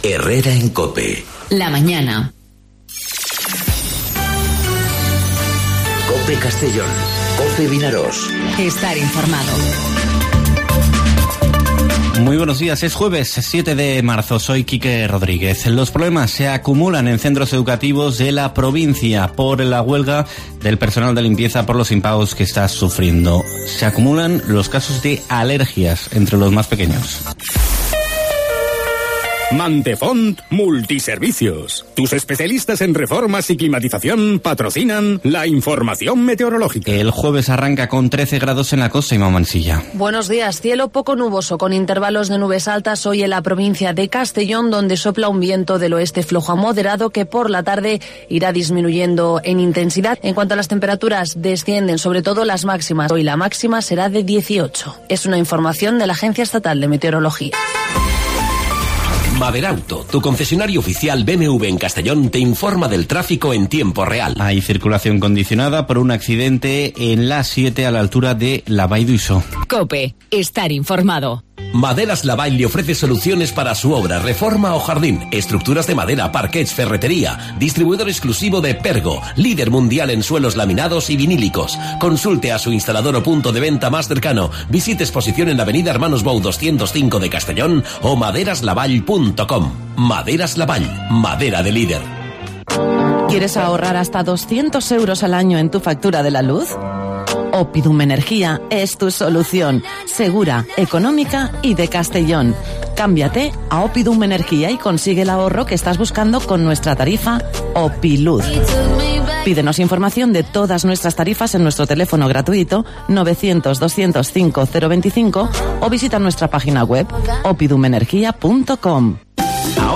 Informativo Herrera en COPE Castellón (07/03/2019)